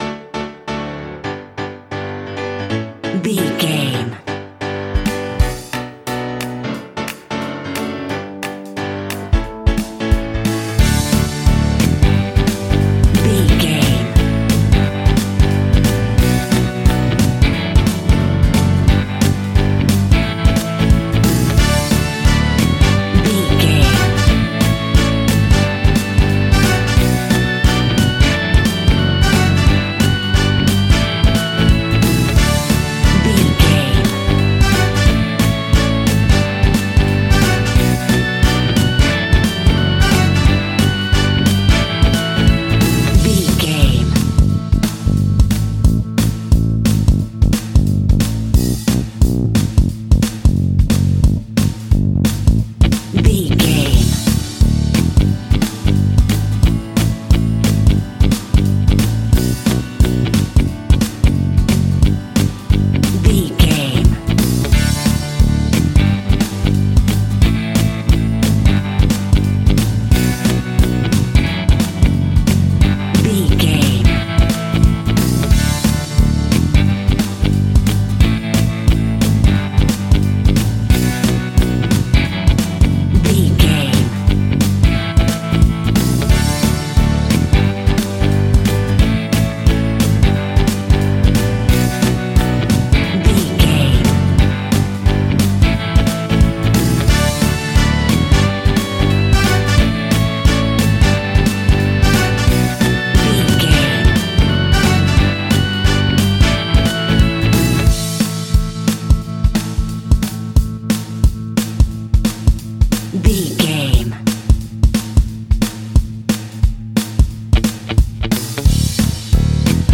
Fast paced
Uplifting
Ionian/Major
D
pop rock
indie pop
fun
energetic
acoustic guitars
drums
bass guitar
electric guitar
piano
electric piano
organ